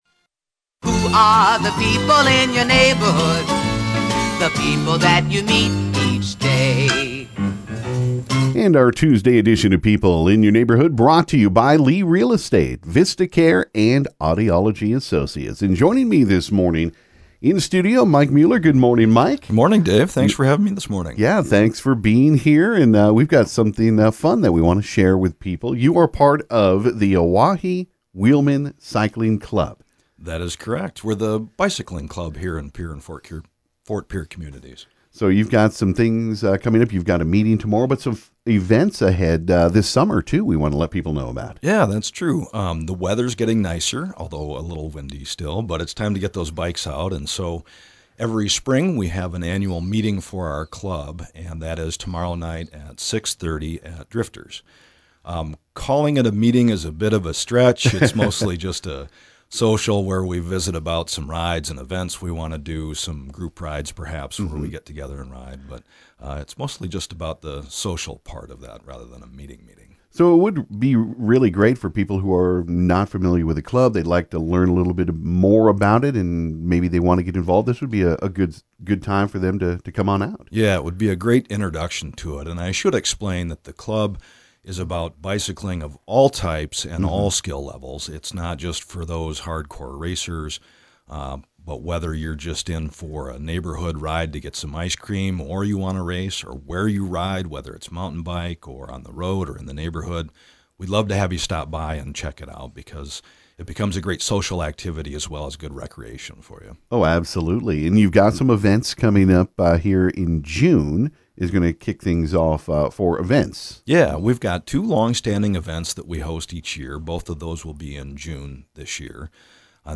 This morning on KGFX